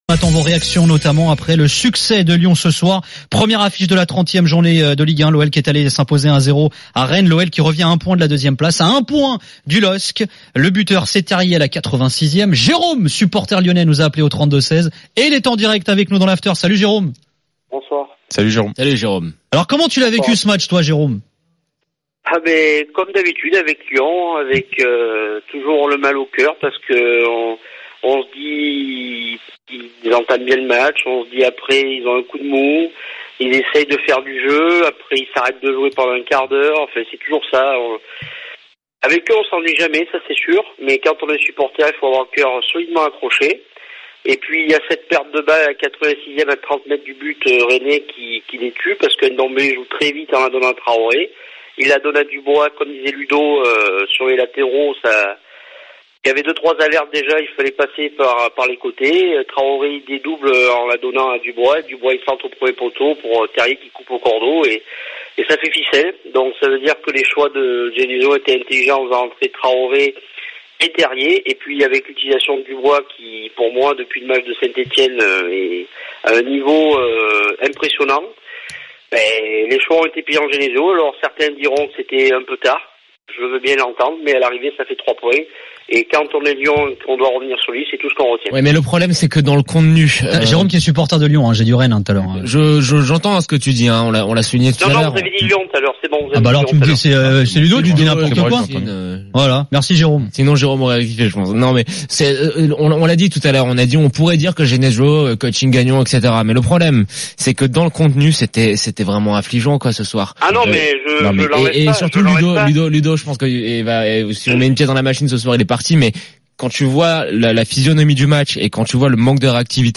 Chaque jour, écoutez le Best-of de l'Afterfoot, sur RMC la radio du Sport.